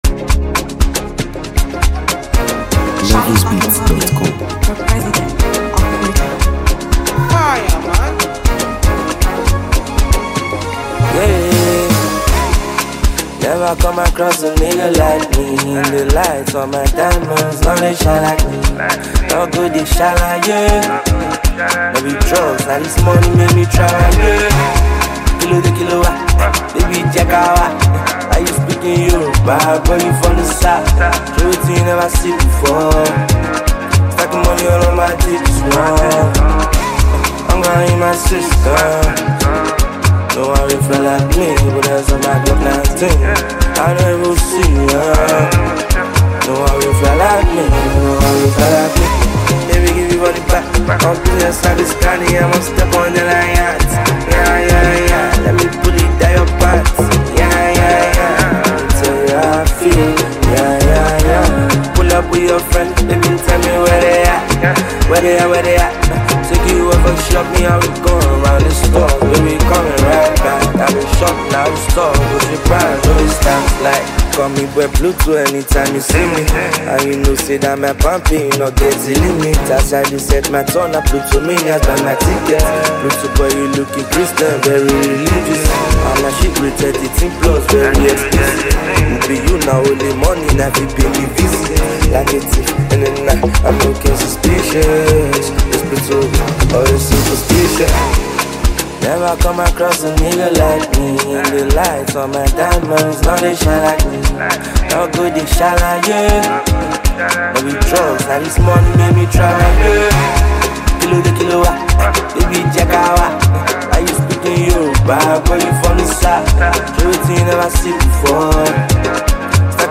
Nigeria Music